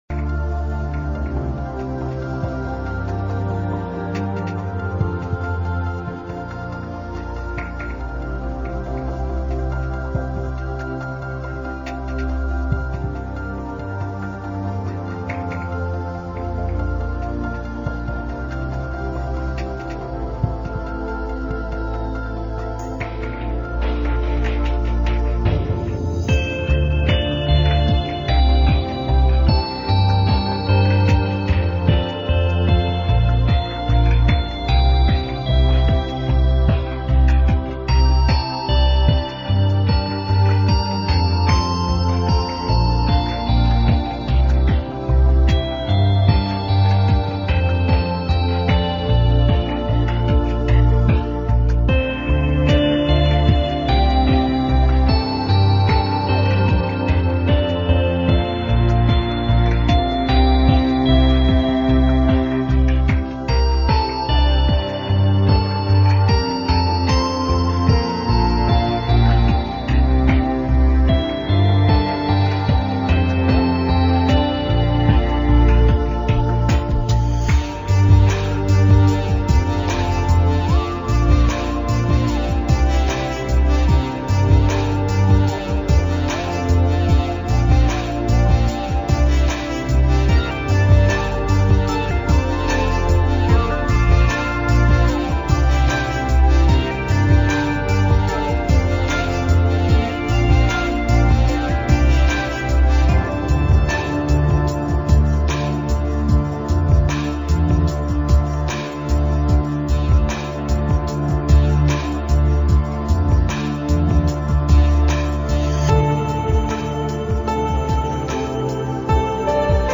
当这些寓意饱满的乐声在耳边打开，一种宁静又神圣的悠远，一些虚幻又神秘的牵念，就持续不断地浮游过来。